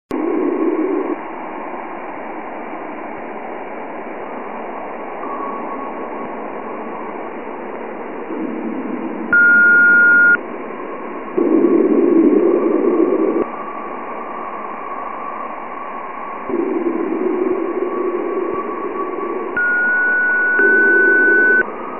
Это электромагнитные колебания, переведенные в слышимый диапазон.
Звучание плазменных волн в юпитерианской ионосфере